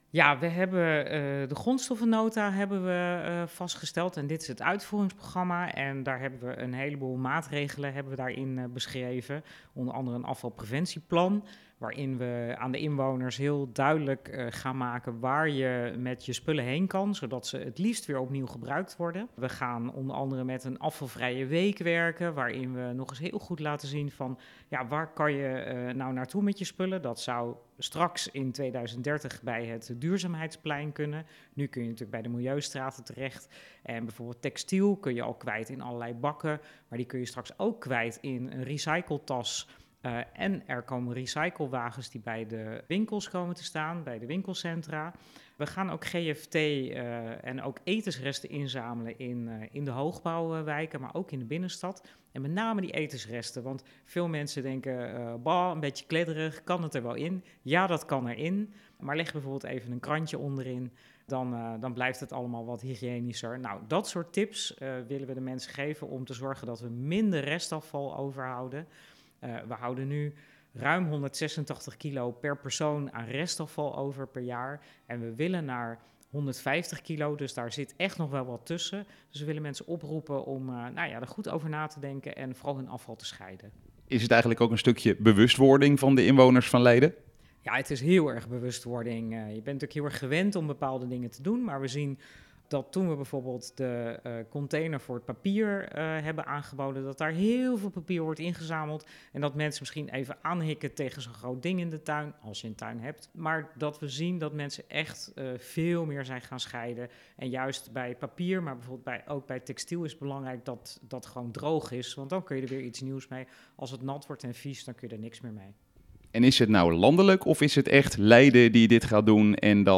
Wethouder-Yvonne-over-afval.mp3